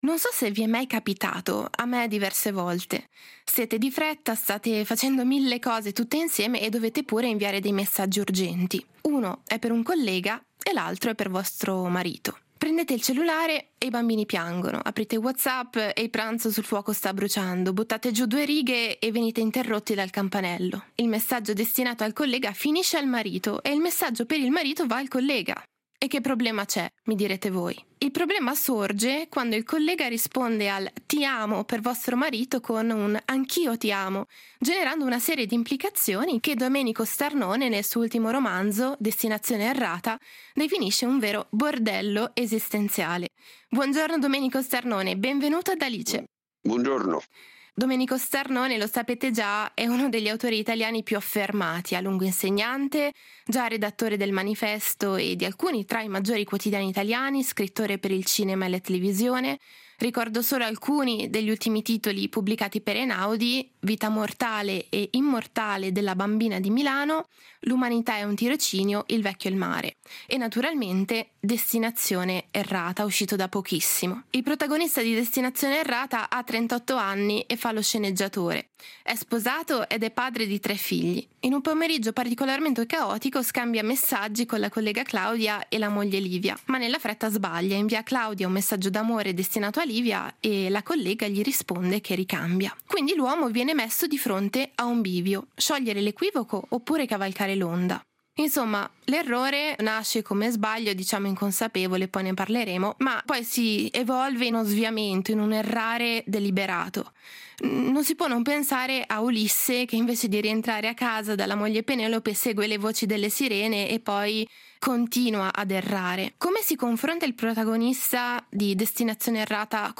Intervista a Domenico Starnone